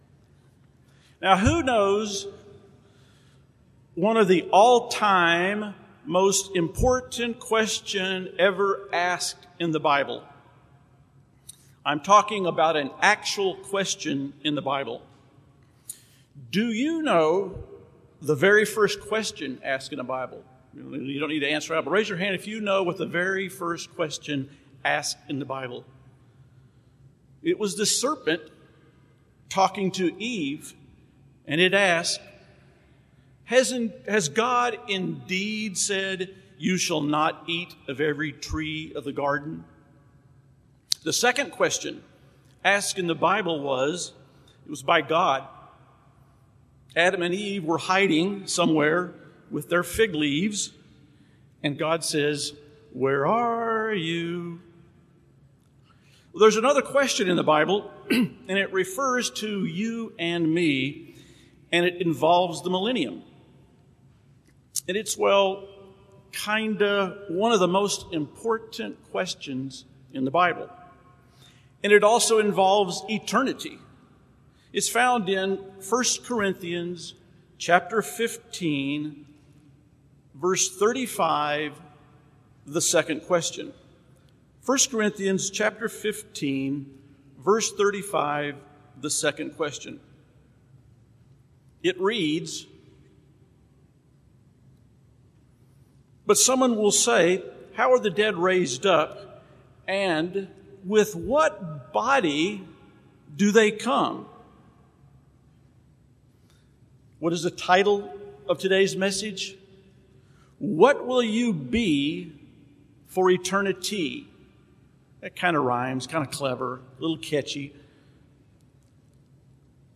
This sermon was given at the St. George, Utah 2020 Feast site.